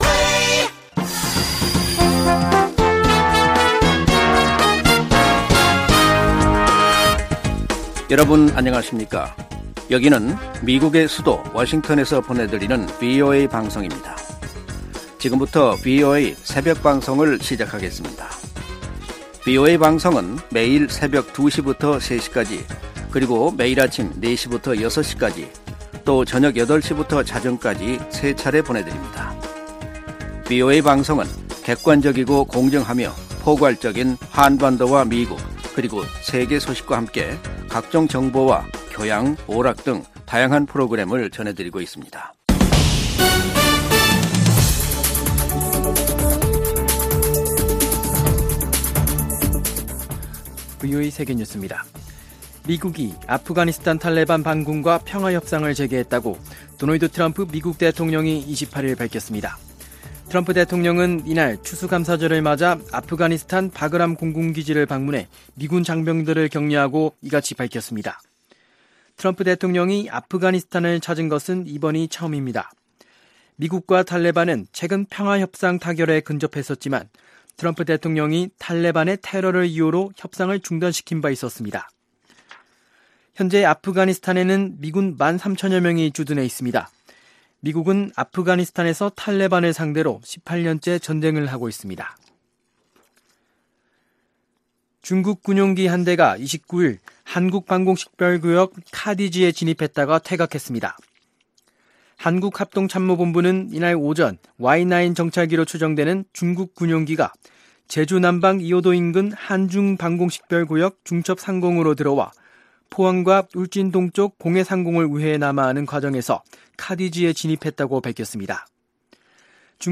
VOA 한국어 '출발 뉴스 쇼', 2019년 11월 30일 방송입니다. 북한이 비핵화 협상 ‘연말 시한’을 앞두고 신형 발사체 발사를 계속하고 있는데 대해 전문가들은 미국의 태도 변화를 압박하기 위한 것이라고 분석했습니다. 미국의 주요 언론들이 비핵화 협상에서의 ‘연말 시한’에 주목하면서 별다른 성과가 없을 경우, 2017년의 화염과 분노로 되돌아가게 될 수 있다고 우려했습니다.